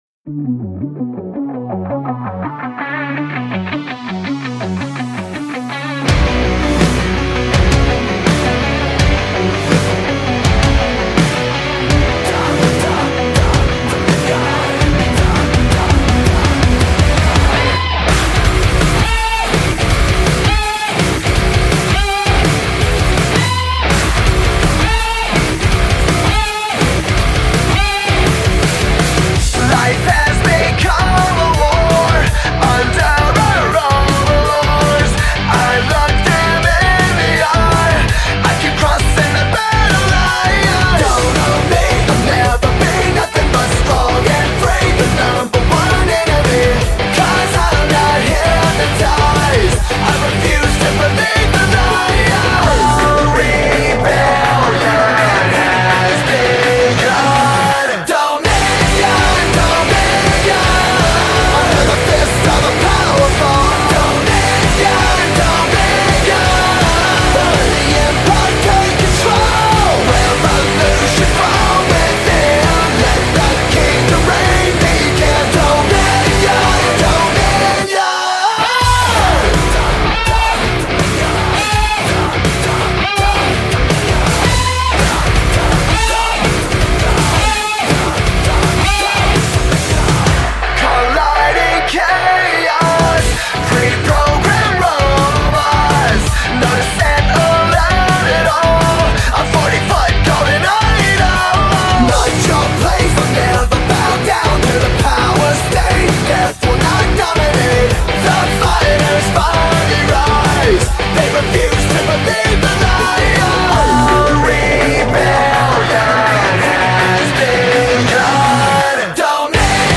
Multi-platinum rockers